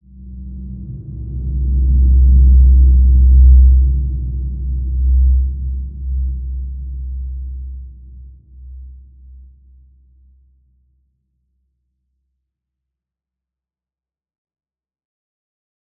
Large-Space-C2-p.wav